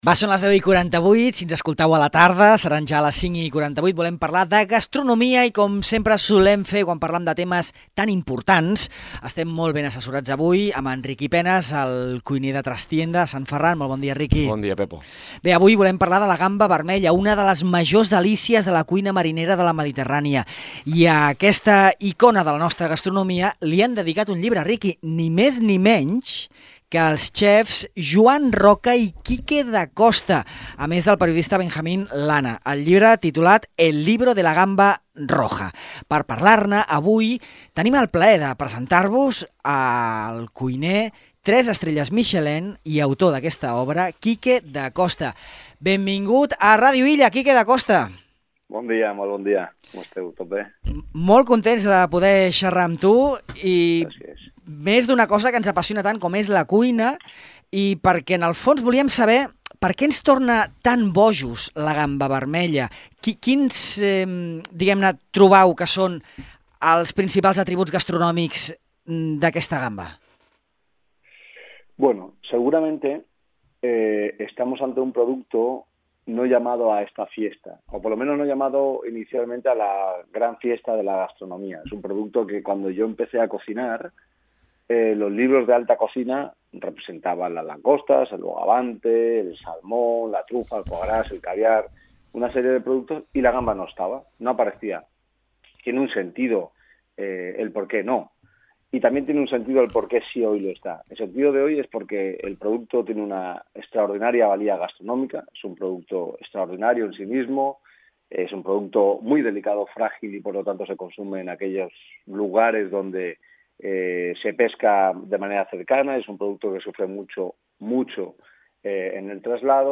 Dacosta reflexiona sobre una de les majors delícies de la cuina marinera de casa nostra en una entrevista